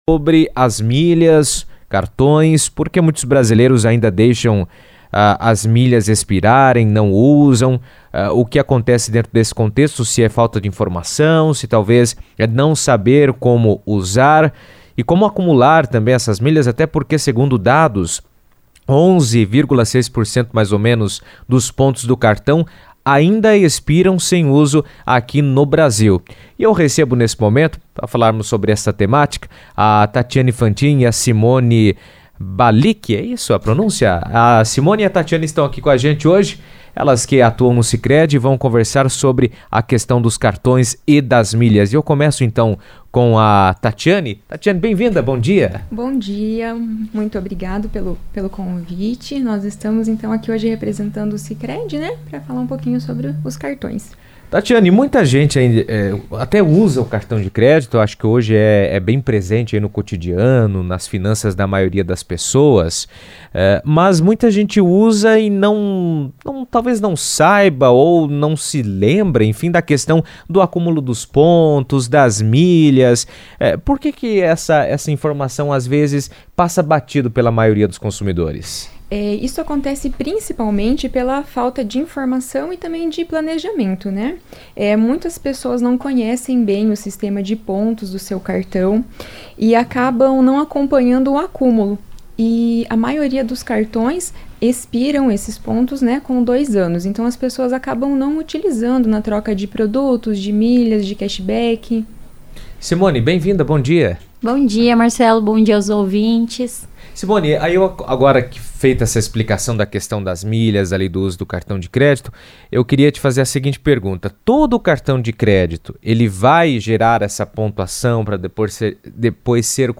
entrevista à CBN